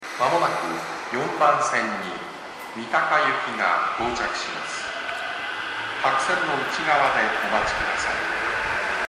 東西線ホームは天井が高く音量もやや小さいので収録が しにくいですね。
接近放送各駅停車　三鷹行き接近放送です。